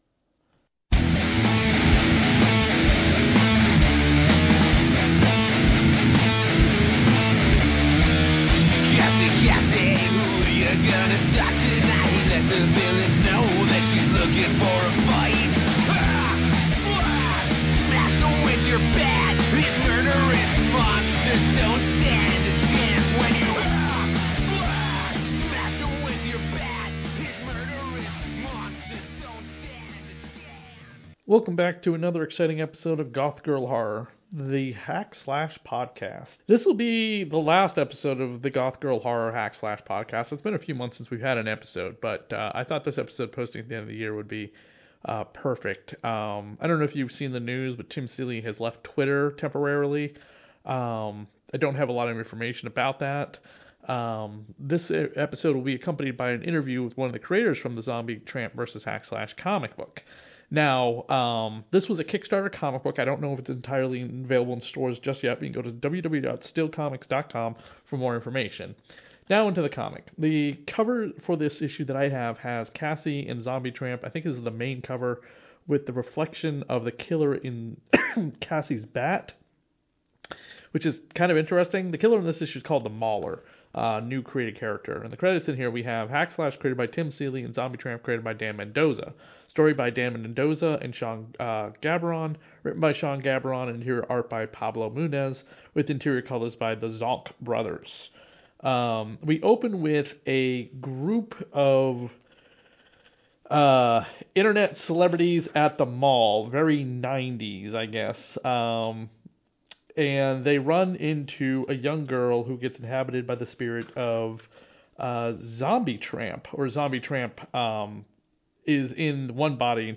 Yes this is the final episode of the Hack/Slash Podcast until we get more comics. This comic was a Kickstarter from the creator of Zombie tramp. We also have an Interview to share with you for this episode.